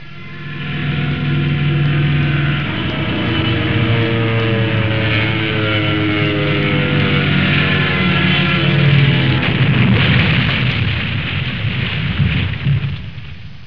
جلوه های صوتی
دانلود صدای طیاره 36 از ساعد نیوز با لینک مستقیم و کیفیت بالا